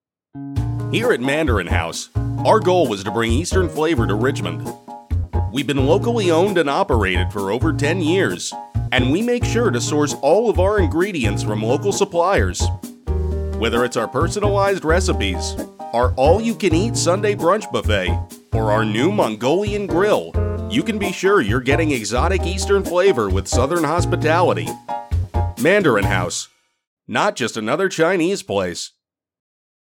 When I talk, people listen, and I can lend that gravitas to your story with a rich and clear sound.
Ad Sample